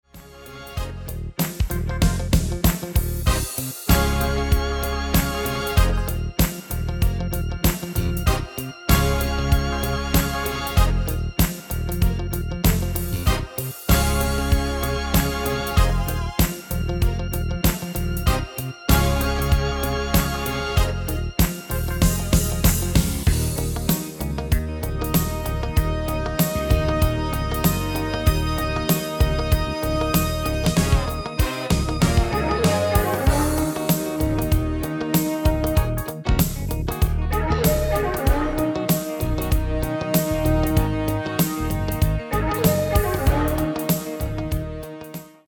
Demo/Koop midifile
Genre: Disco
Toonsoort: D#